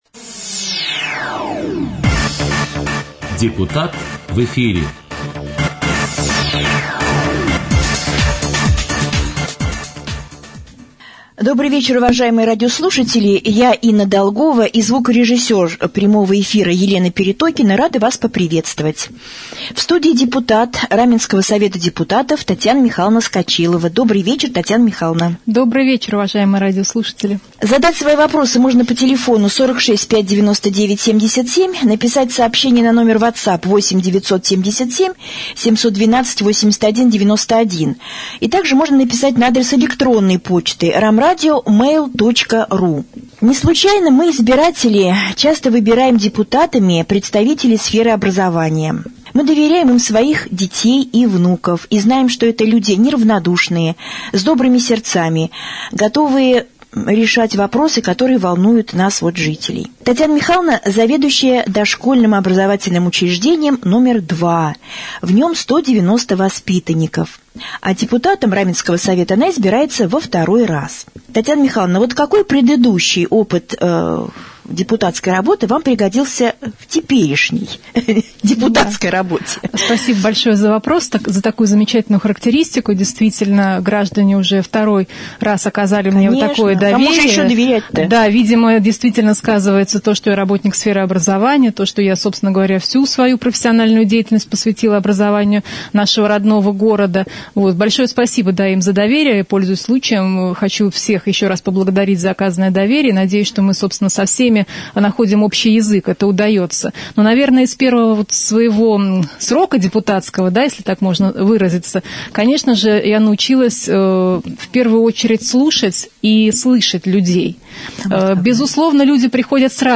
В четверг, 29 октября, гостем прямого эфира на Раменском радио стала депутат Совета депутатов Раменского г.о., заведующий Детским садом комбинированного вида №2 Татьяна Скочилова.
prjamoj-jefir-2.mp3